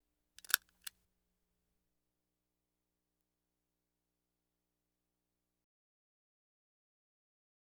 Stapler Hand Type Sound Effect
Download a high-quality stapler hand type sound effect.
stapler-hand-type.wav